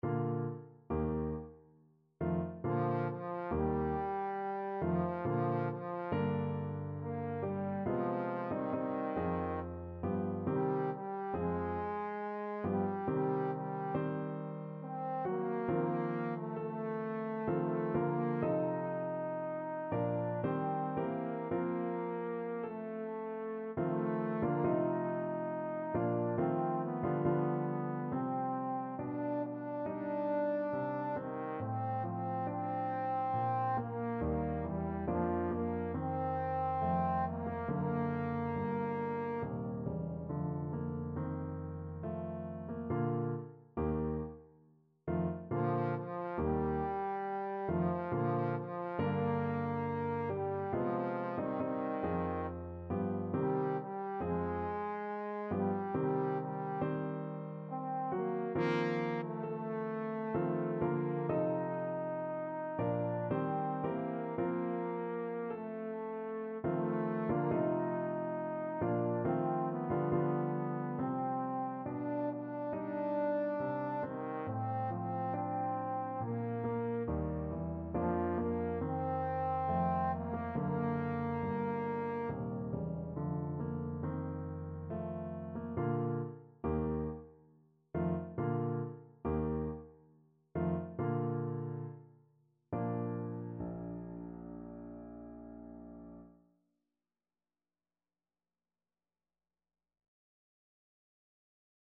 Classical Schumann, Robert Seit ich ihn gesehen (No. 1 from Frauenliebe und Leben) Trombone version
Bb major (Sounding Pitch) (View more Bb major Music for Trombone )
Eb4-Eb5
3/4 (View more 3/4 Music)
Larghetto =69
Classical (View more Classical Trombone Music)